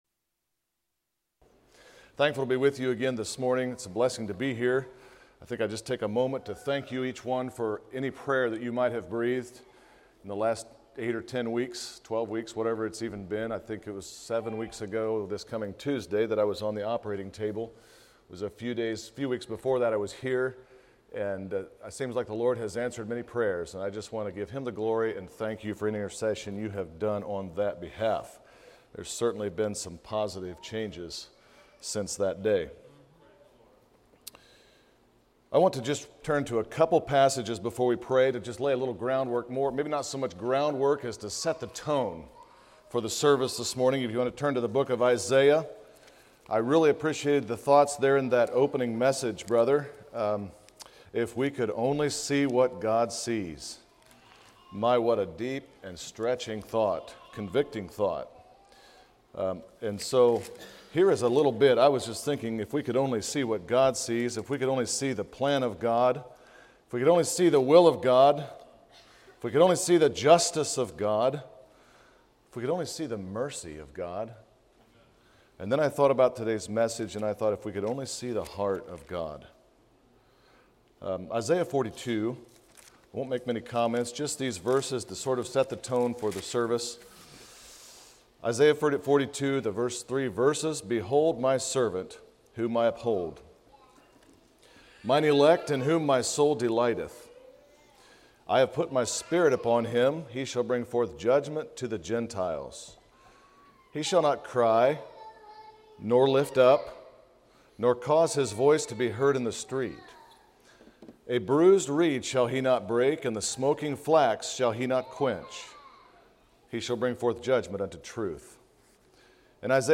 Sermons of 2020 - Blessed Hope Christian Fellowship